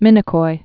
(mĭnĭ-koi)